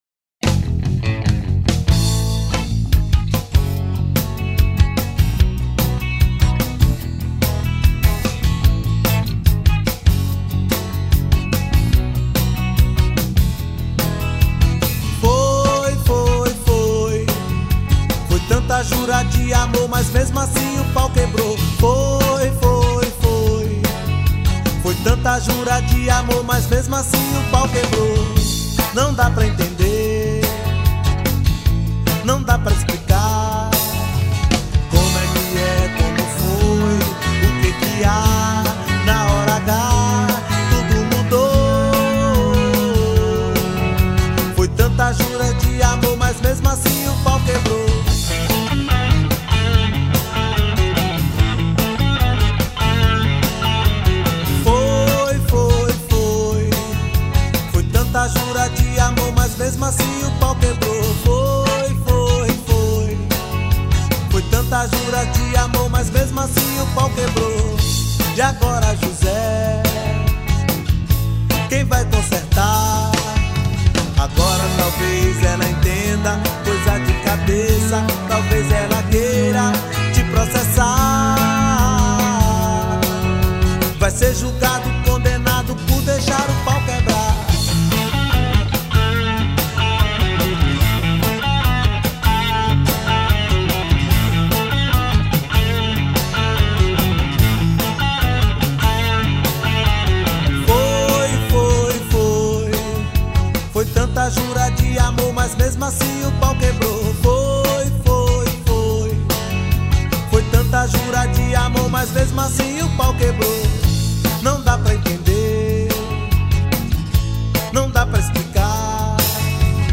2614   02:32:00   Faixa: 6    Reggae